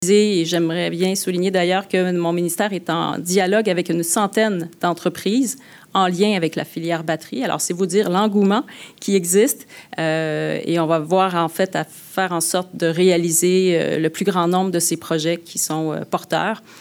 L’investissement qui a été annoncé en conférence de presse lundi avant-midi est donc de 327,6 M$.
Pour la ministre de l’Économie, de l’Innovation et de l’Énergie du Québec, Christine Fréchette, ça se bouscule pour rejoindre ce secteur en pleine croissance.